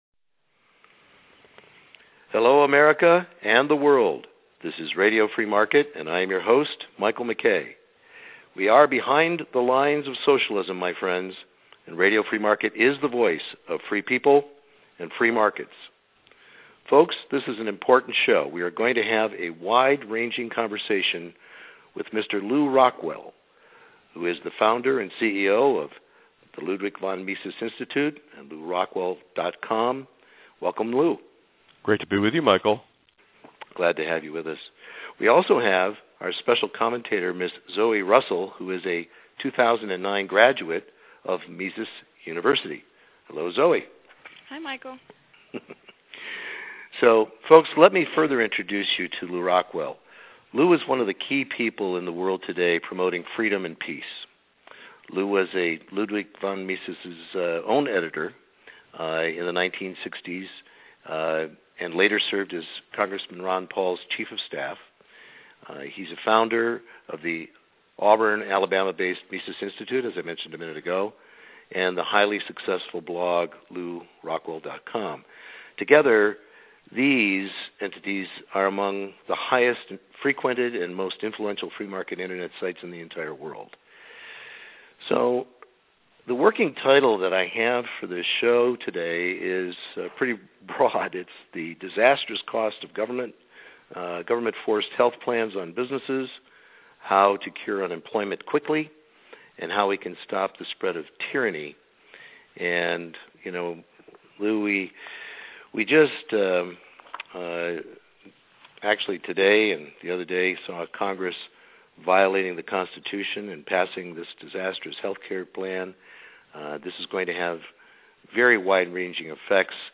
** Lew Rockwell ** – An Exclusive Interview and Wide Ranging Conversation.